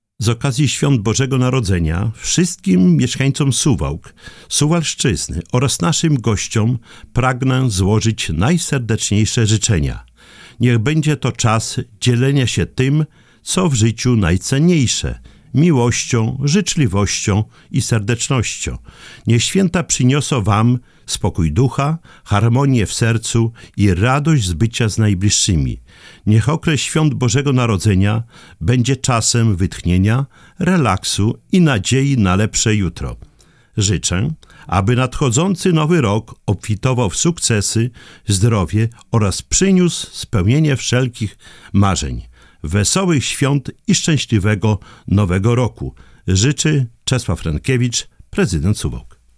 Świąteczne życzenia prezydenta Suwałk